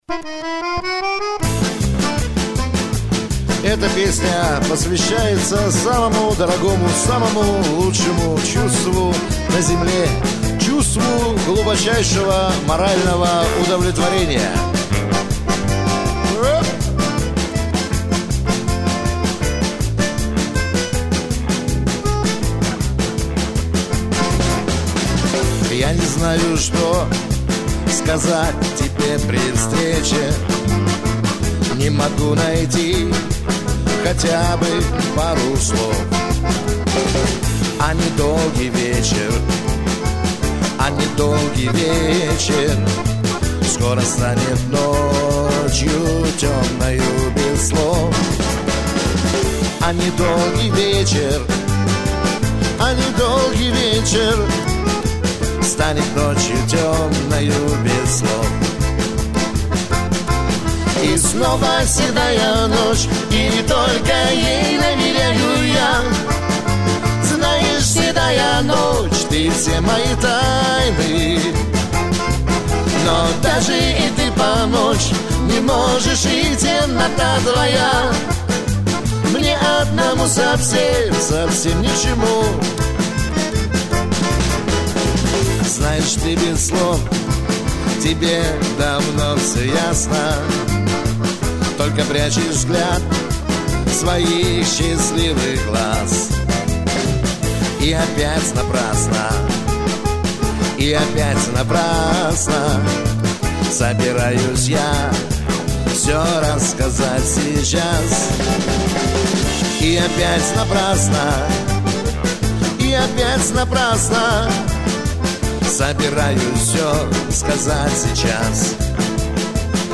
баянист
барабанщик